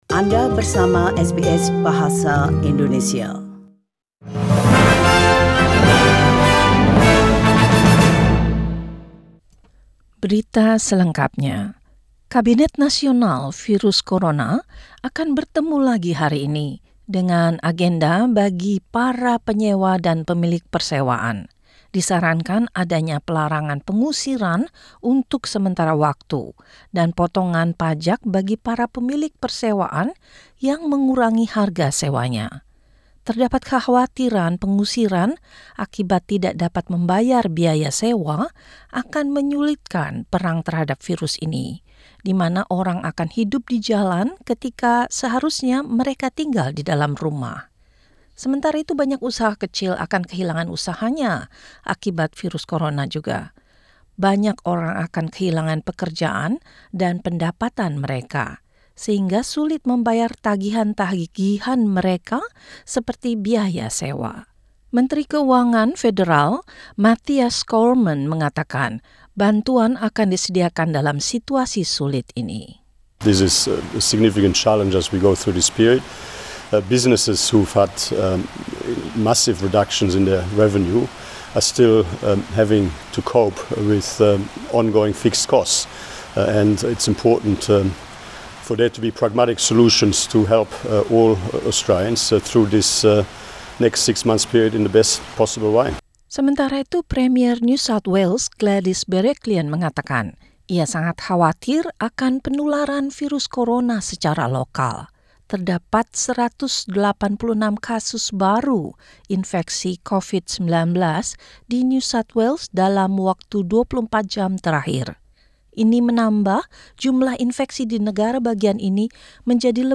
SBS Radio News in Indonesian - 27 Maret 2020